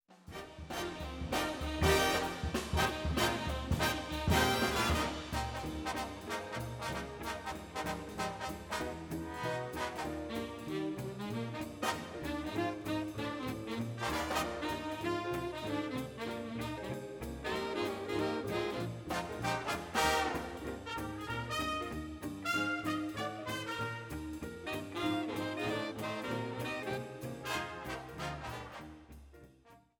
für Holzbläserquintett